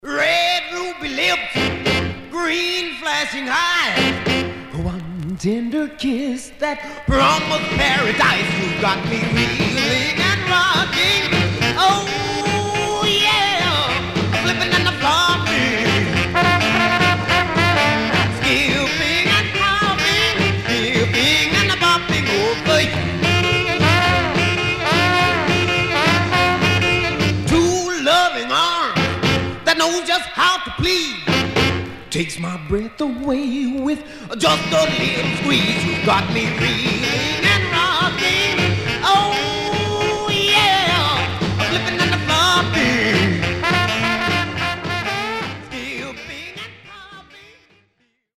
Mono
Rythm and Blues